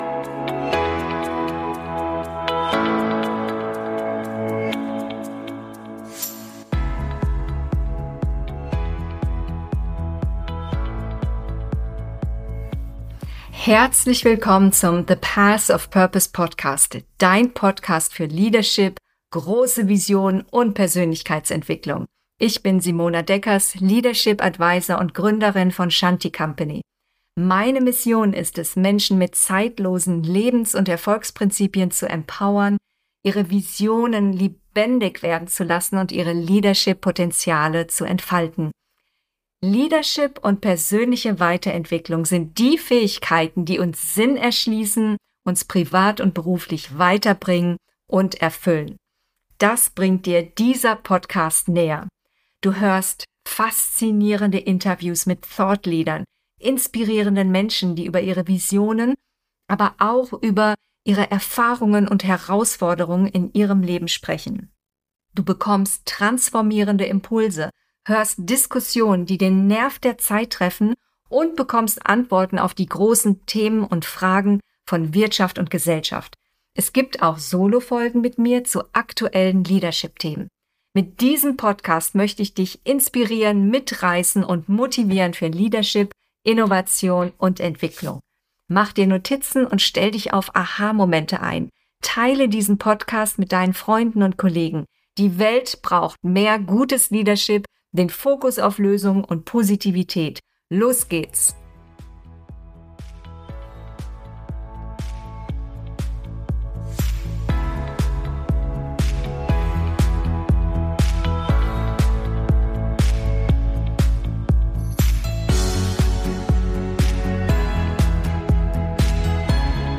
Burnout: Warnsignale erkennen und vorbeugen - Interview